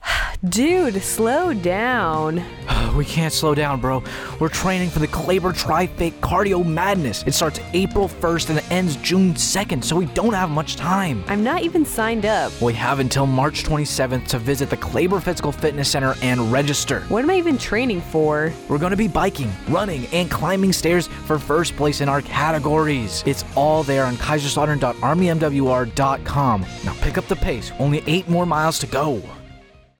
A 30-second radio spot promoting the Kleber Tri Fit Cardio Madness event that will air from March 10, 2026, to March 27, 2026. This event encourages services members to maintain and exceed fitness requirements for a more war-ready force.